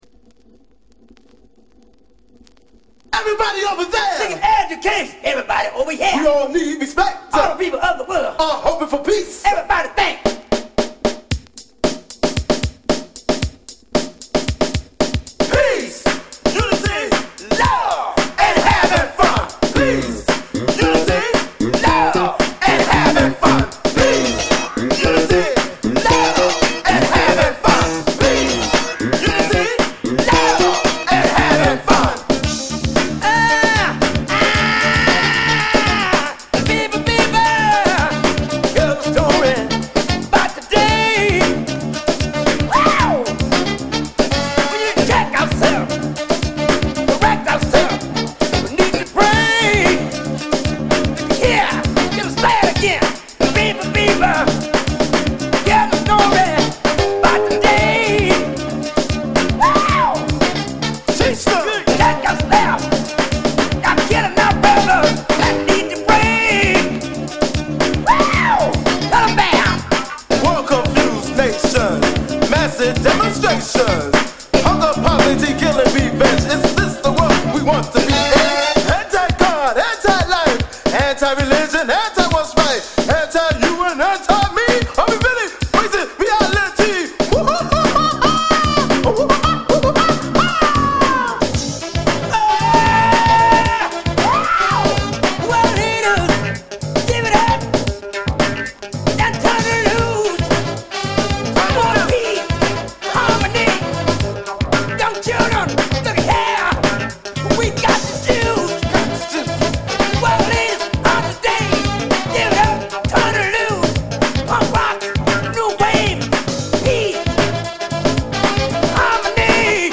> HIPHOP/R&B
オールドスクールの金字塔！